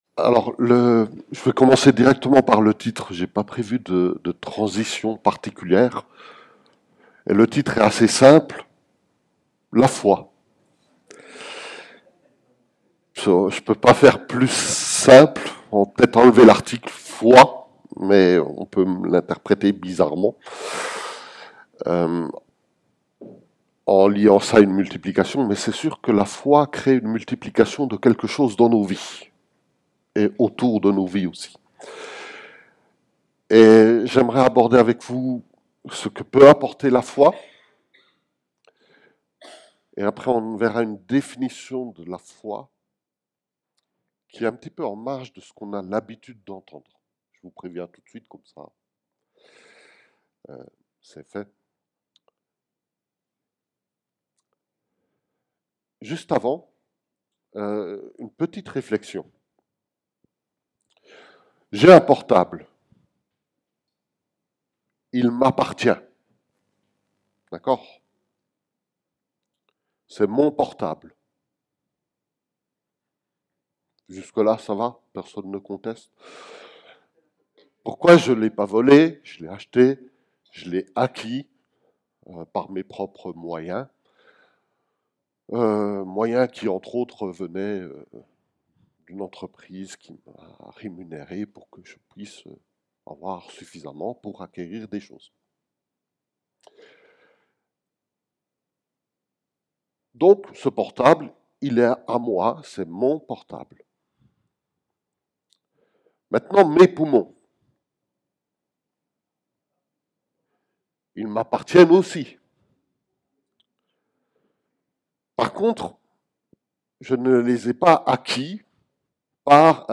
Culte hebdomadaire - EEBS - Église Évangélique Baptiste de Seloncourt